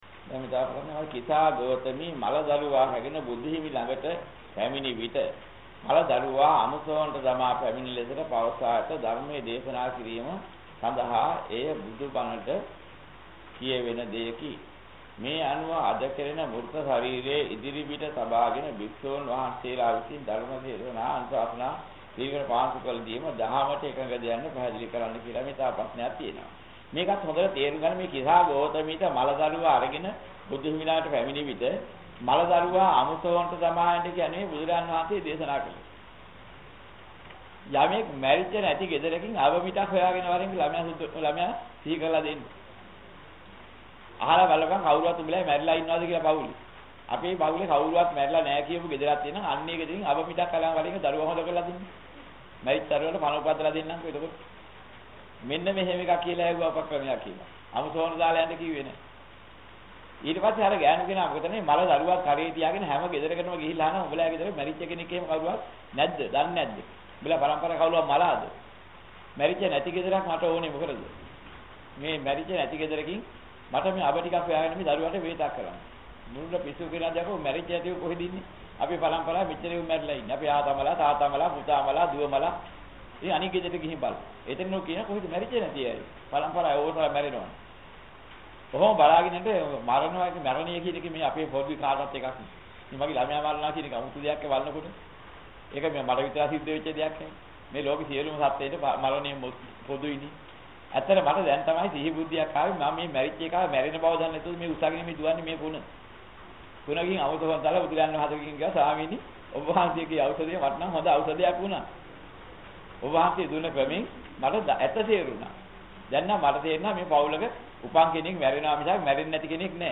මෙම දේශනාවේ සඳහන් වන ධර්ම කරුණු: